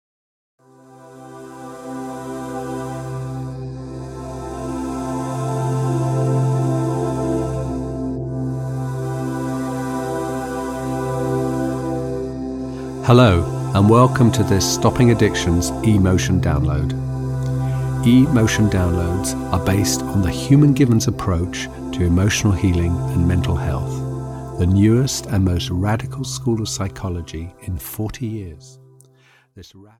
Stopping Addictions (EN) audiokniha
Ukázka z knihy